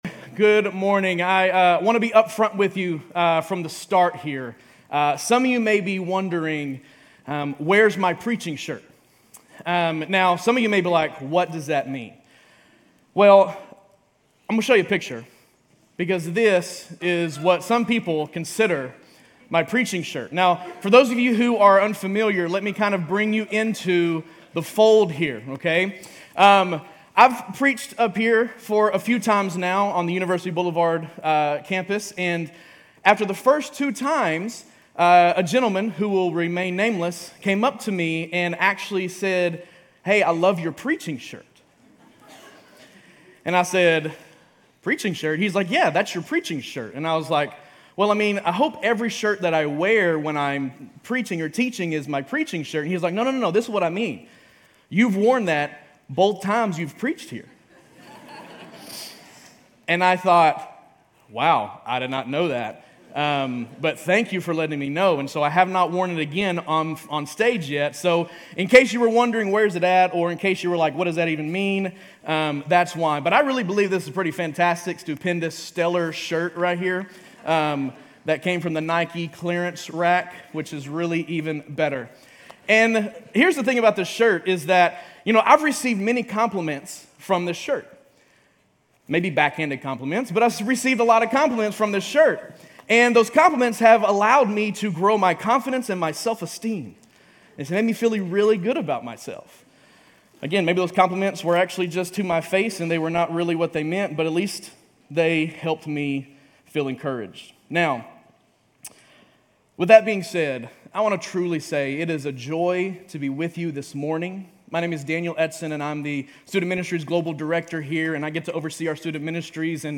Grace Community Church University Blvd Campus Sermons Luke 24:1-12 Apr 09 2023 | 00:30:04 1x 00:00 / 00:30:04 Subscribe Share RSS Feed Share Link Embed